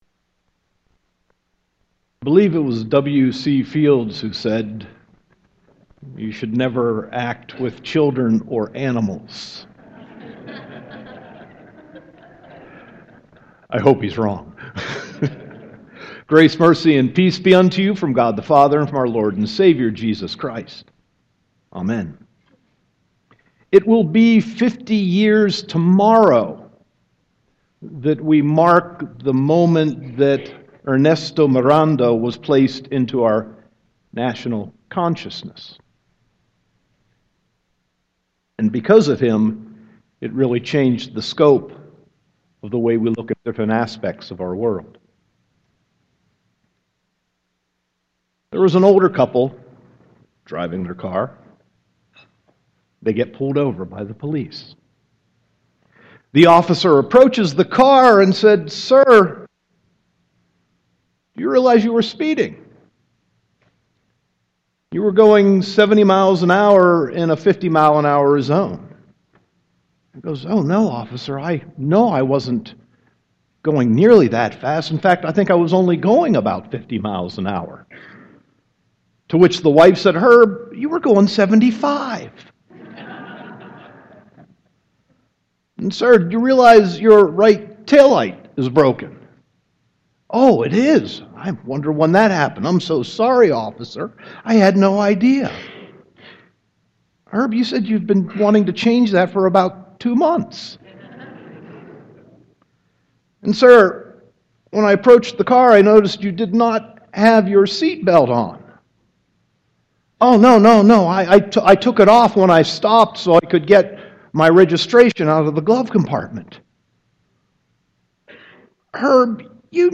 Sermon 6.12.2016